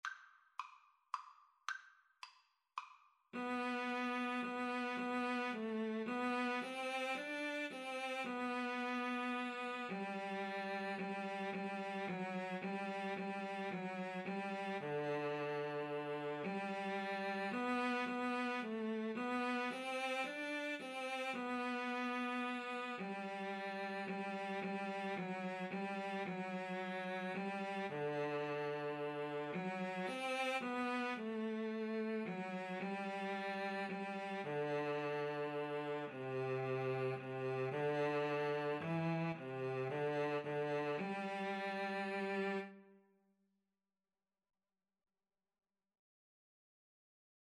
3/4 (View more 3/4 Music)